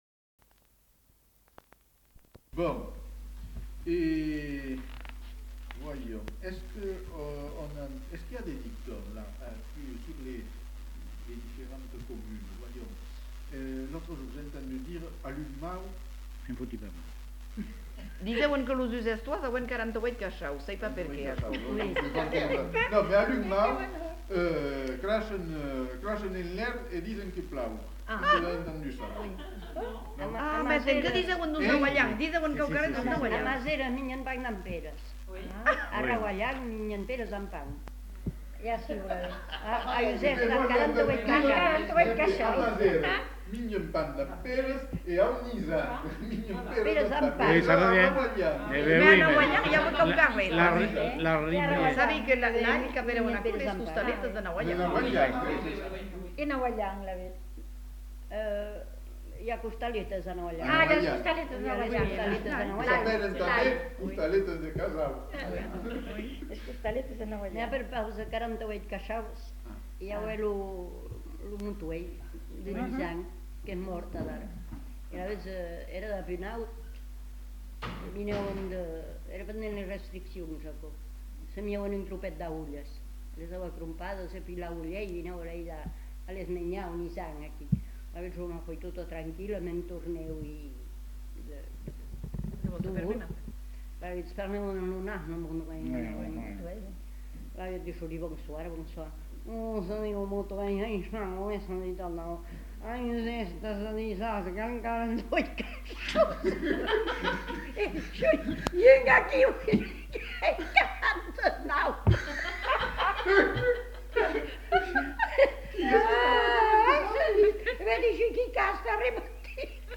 Lieu : Uzeste
Genre : forme brève Effectif : 2 Type de voix : voix d'homme ; voix de femme Production du son : parlé